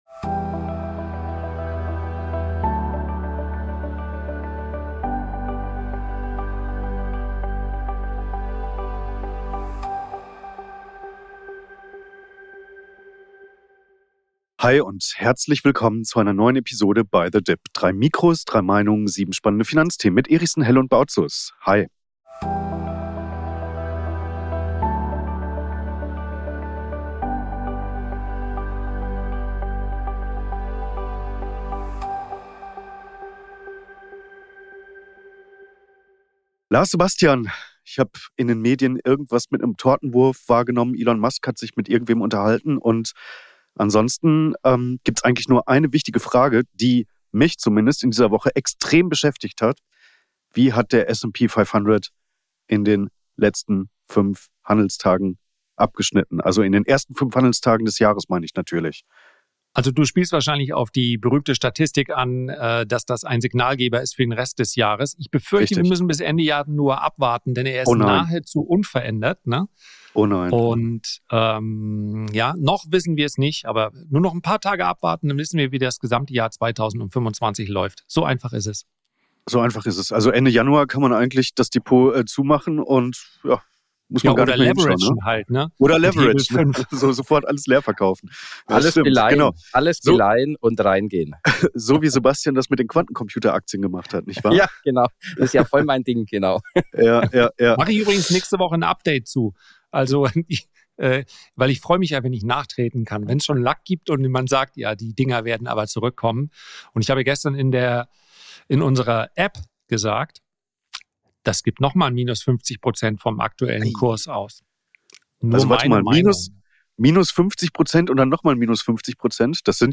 3 Mikrofone, 3 Meinungen